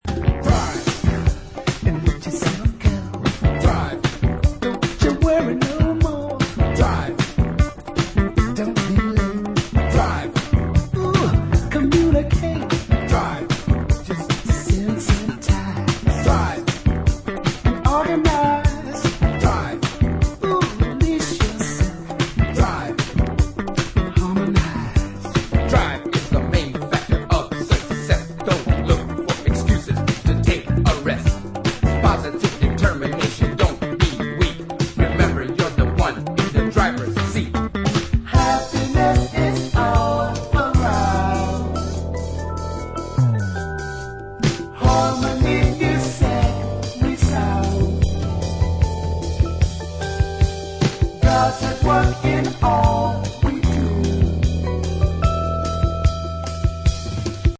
Remastered from the original master tapes.